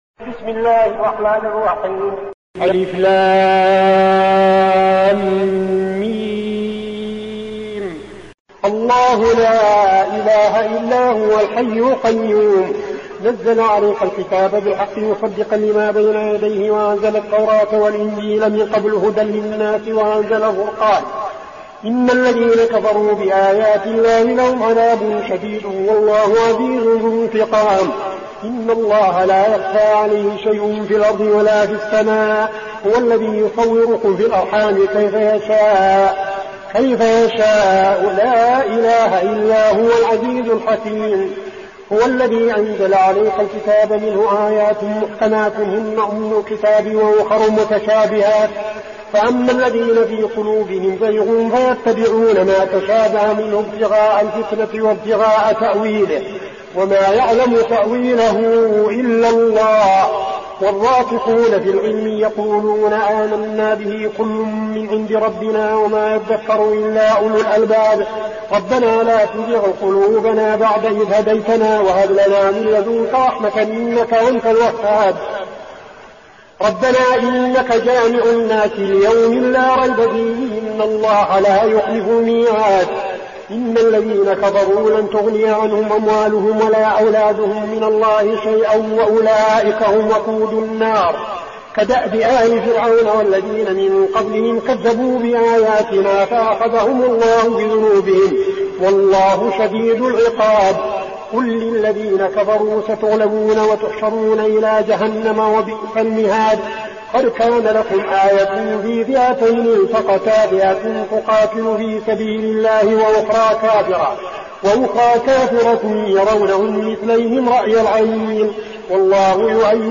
المكان: المسجد النبوي الشيخ: فضيلة الشيخ عبدالعزيز بن صالح فضيلة الشيخ عبدالعزيز بن صالح آل عمران